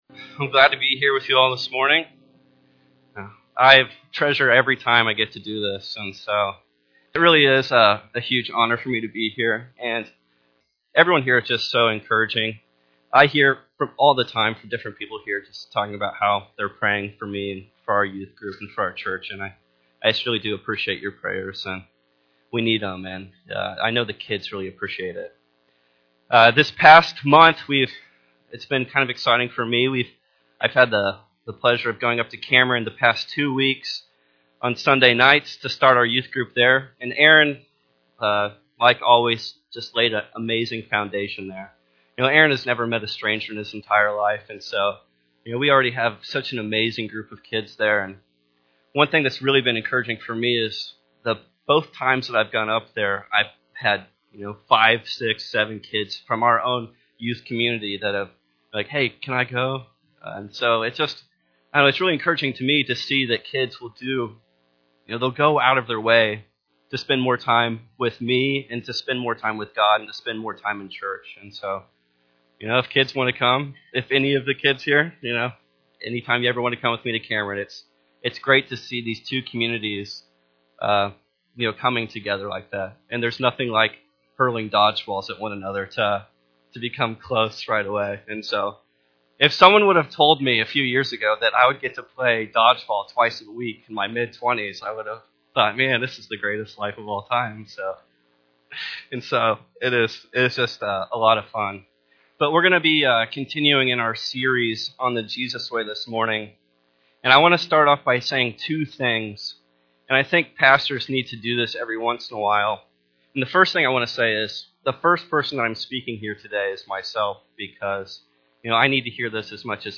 Sunday Morning Service The Jesus Way